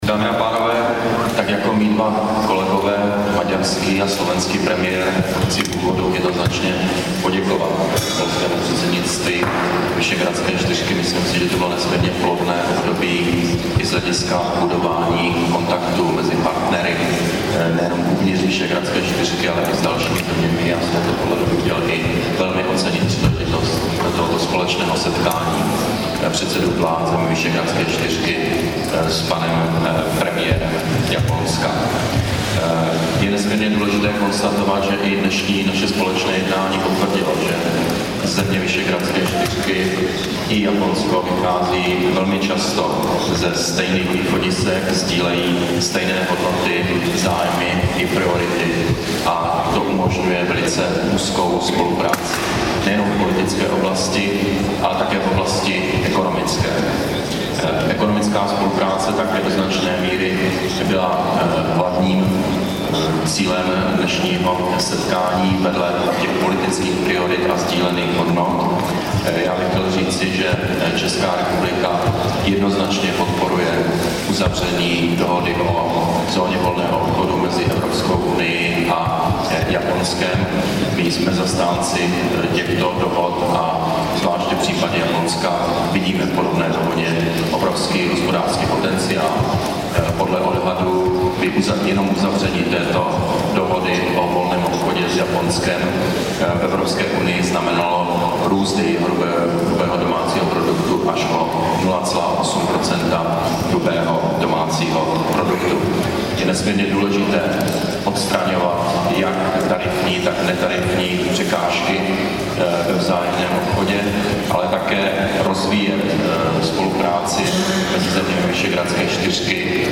Dohoda o volném obchodu mezi Evropskou unií a Japonskem může přinést růst hrubého domácího produktu. Řekl to premiér Petr Nečas ve Varšavě po jednání V4 s japonským premiérem.